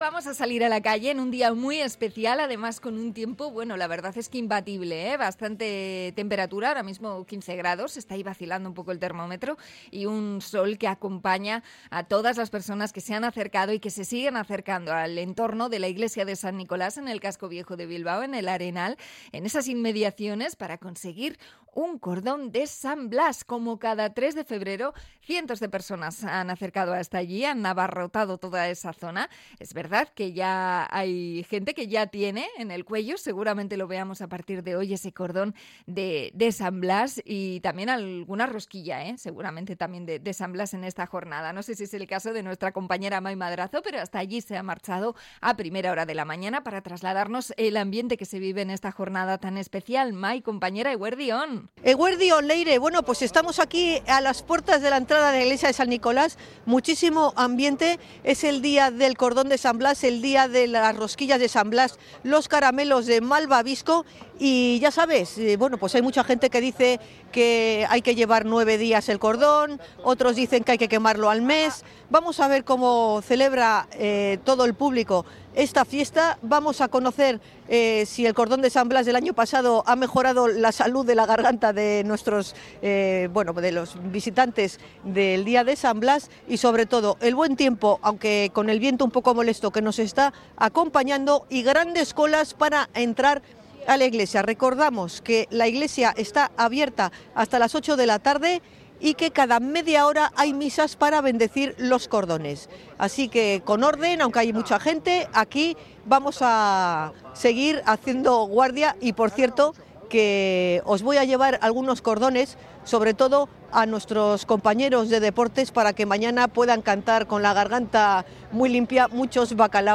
Esta mañana cordones de San Blas / Radio Popular de Bilbao